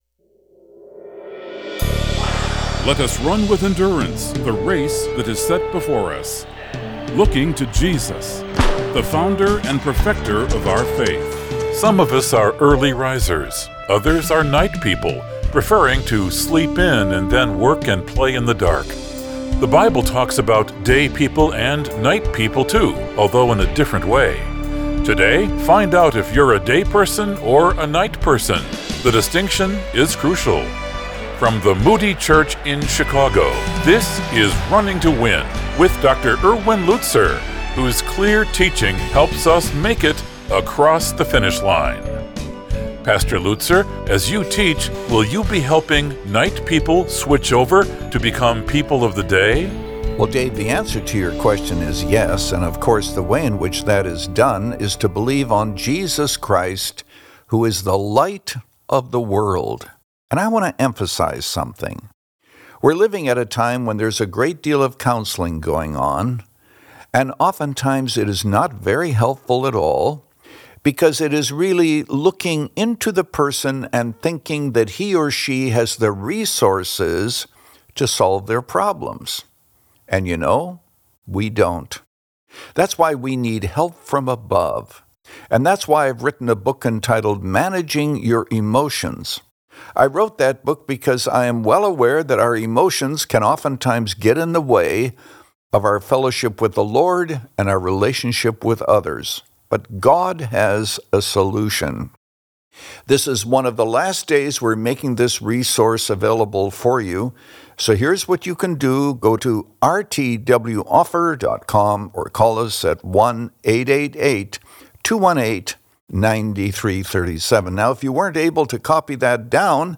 But with the Bible front and center and a heart to encourage, Pastor Erwin Lutzer presents clear Bible teaching, helping you make it across the finish line. Since 2011, this 25-minute program has provided a Godward focus and features listeners’ questions.